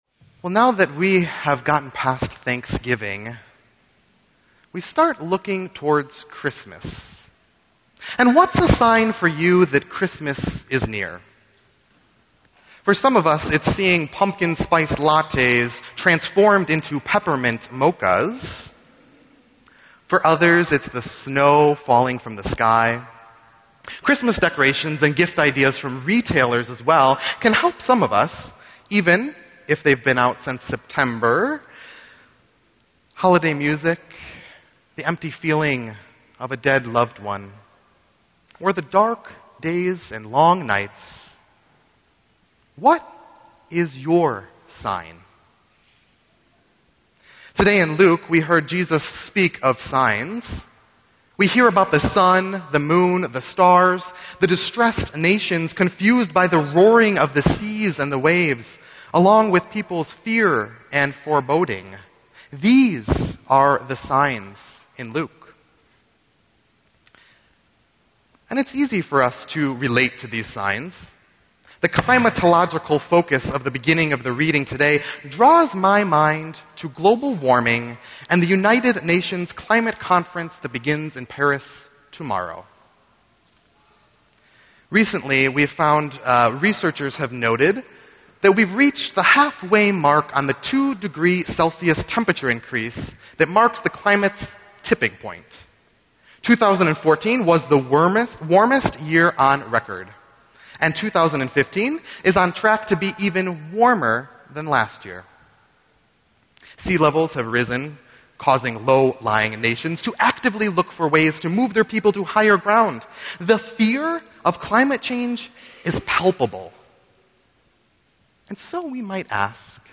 Wicker Park Lutheran Church Preaching Fall 2015